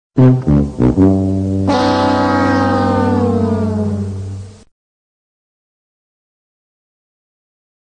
Bah Bump Bah Bummmmm Fail Audio Wave MP3
Ba-Ba-BaBummmm-removed.mp3